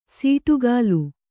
♪ sīṭugālu